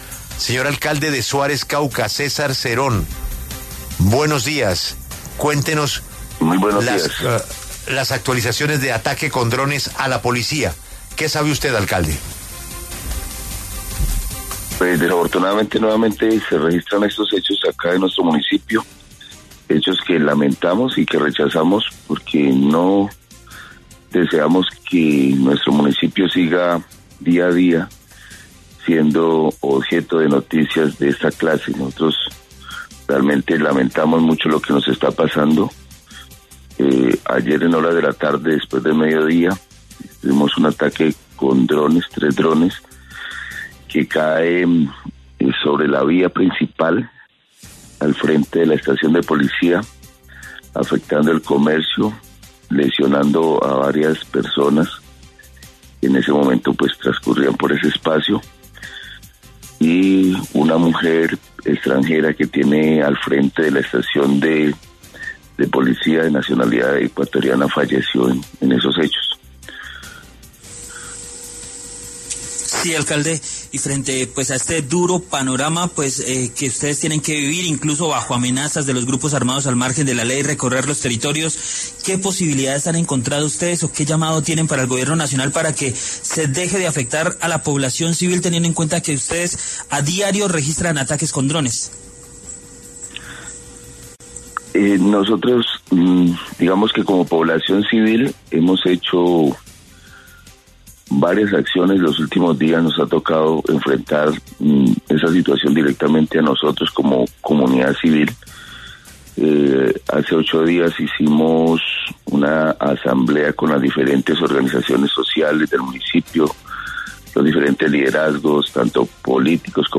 Tras un ataque con explosivos dirigido contra la Estación de Policía de Suárez, Cauca, que dejó como saldo la muerte de una ciudadana ecuatoriana y un menor herido, pasó por los micrófonos de La W el alcalde local, César Cerón, para describir la crisis que se vive en el territorio.